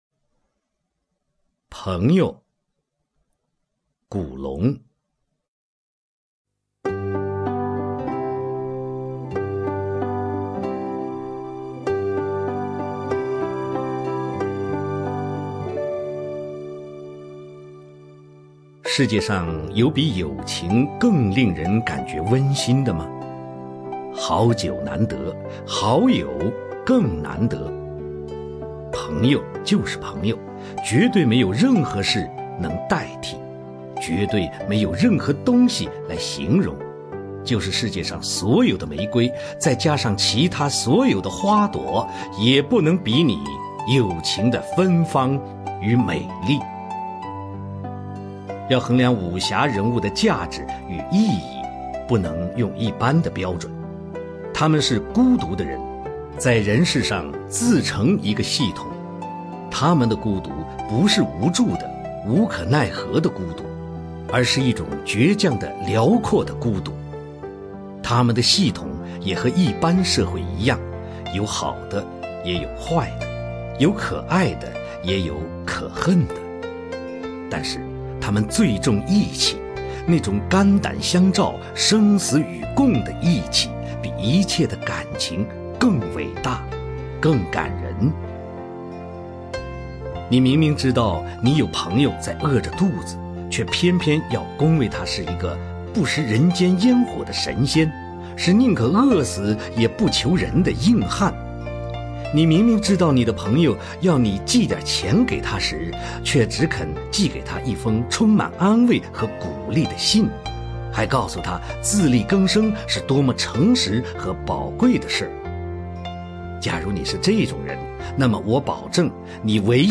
卢吉雄朗诵：《朋友》(古龙)
名家朗诵欣赏 卢吉雄 目录